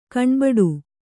♪ kaṇbaḍu